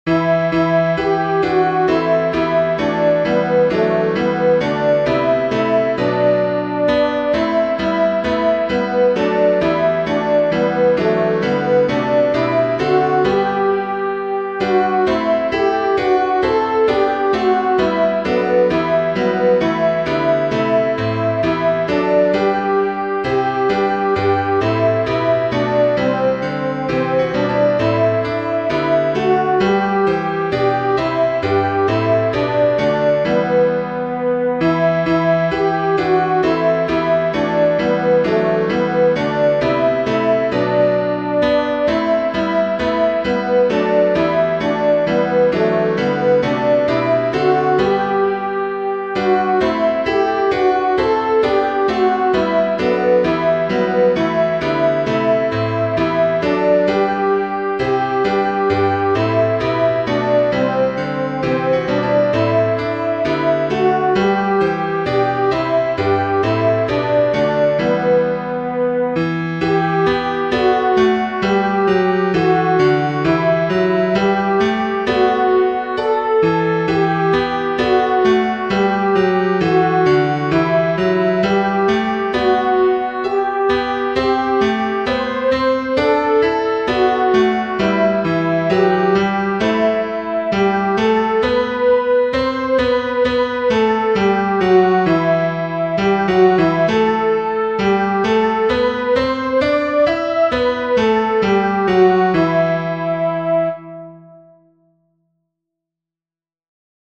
god_rest_you_merry_gentlemen-alto.mp3